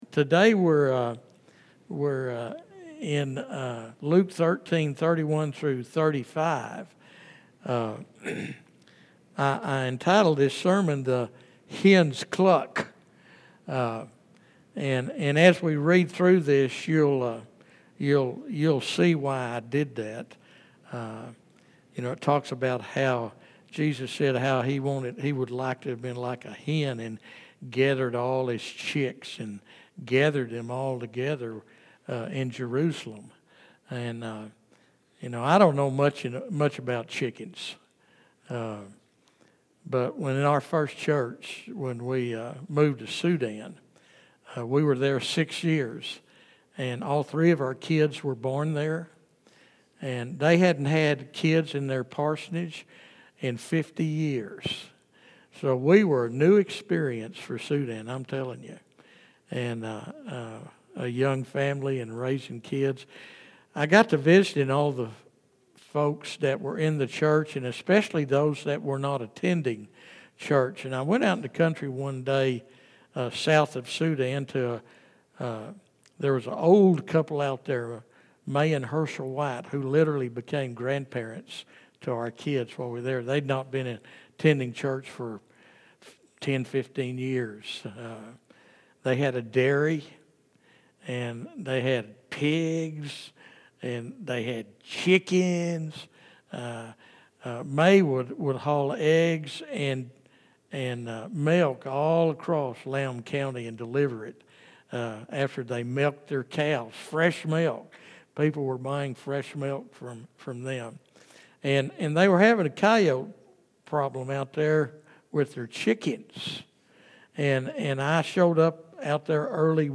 This sermon challenges us to examine our own hearts: Are we gathered under Christ's protective wings, or are we scattering and doing our own thing? It's a call to repentance, a reminder of God's patience, and an encouragement for believers to share the gospel with urgency and boldness.